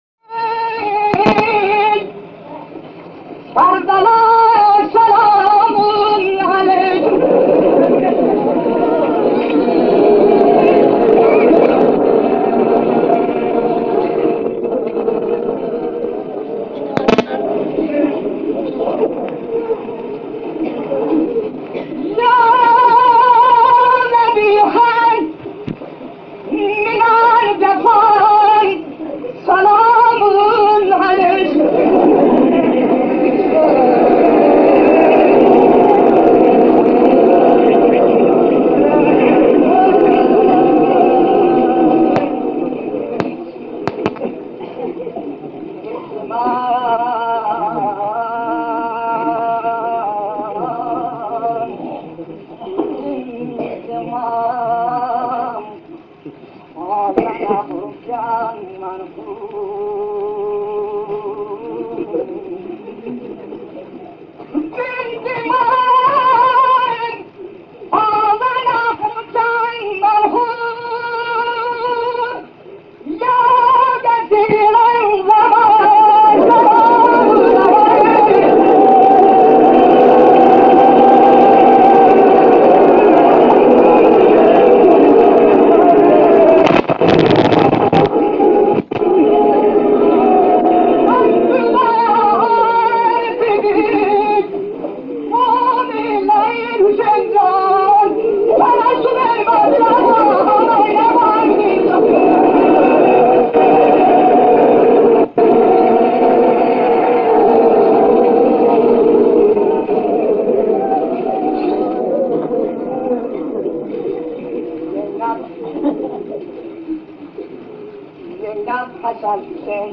روضه ی امام حسین - سلیم موذن زاده با ترافیک رایگان
روضه ی امام حسین